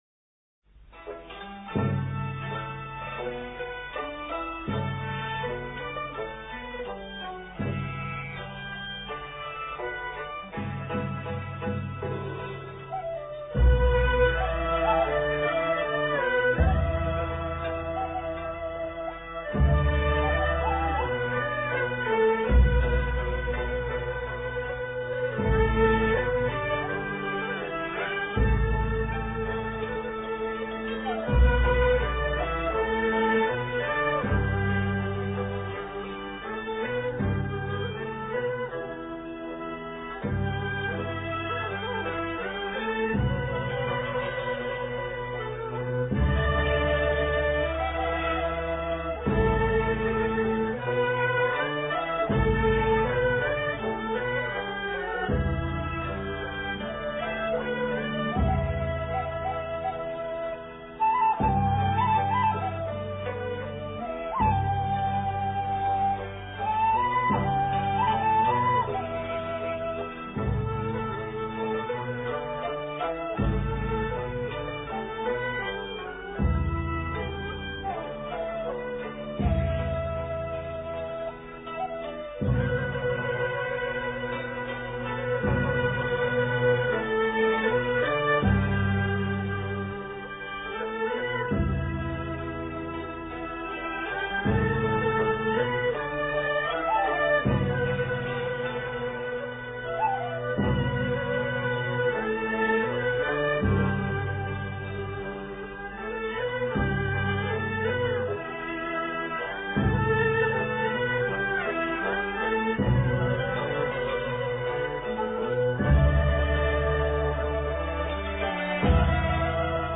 廣東音樂妝臺秋思